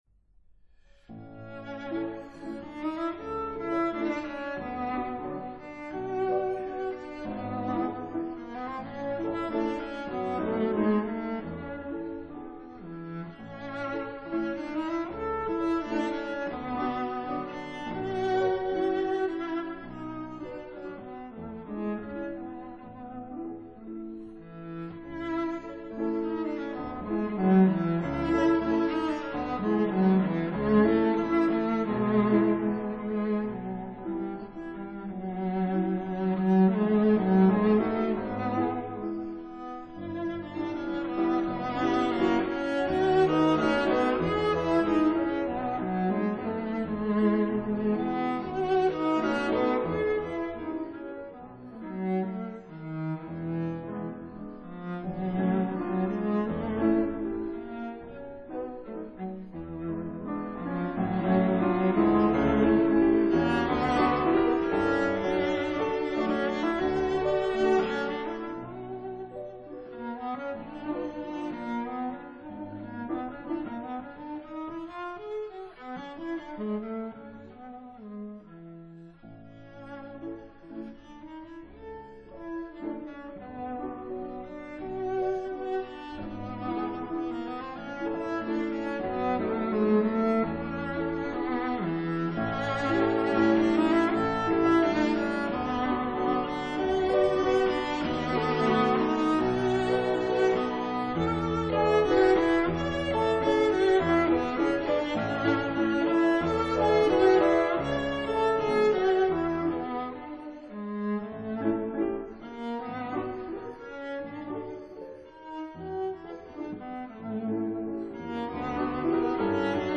Andante tranquillo
Allegretto grazioso